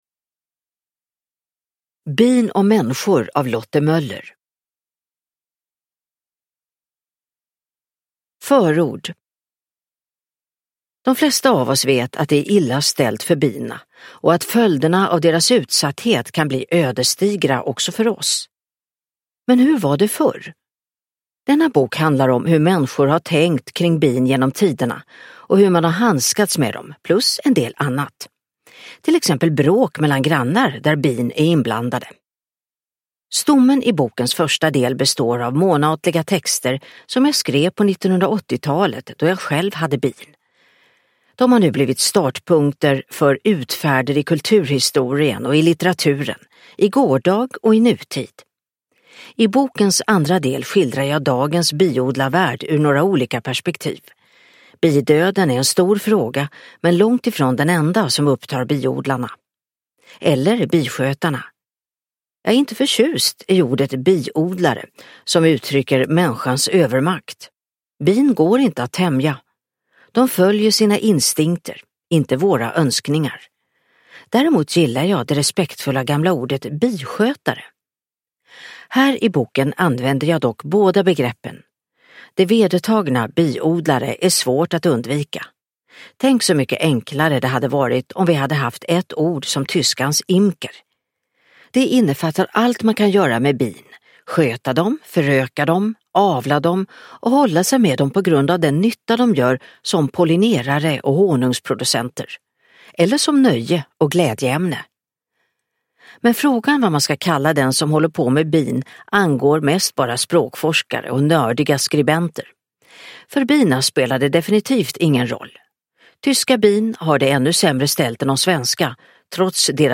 Bin och människor : om bin och biskötare i religion, revolution och evolution samt många andra bisaker – Ljudbok – Laddas ner